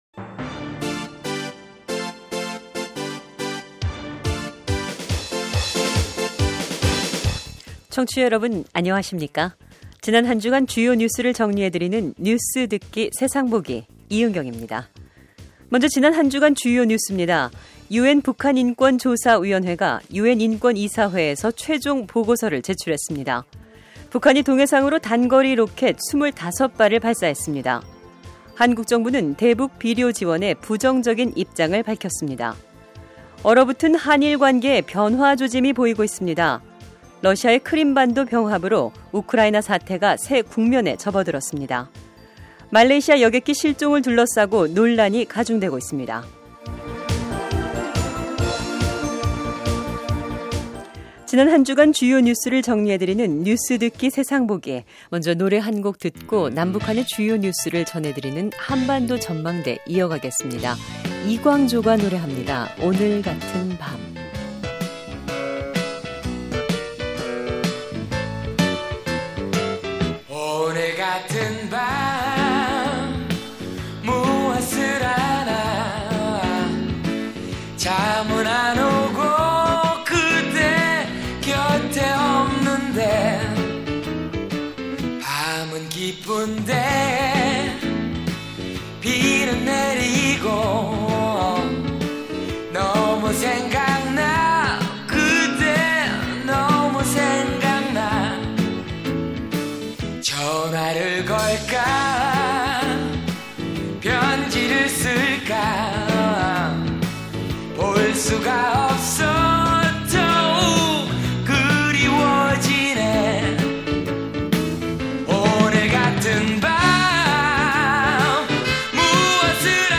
뉴스해설: 북한 단거리 로켓 25발 발사, 한-일 관계 변화 조짐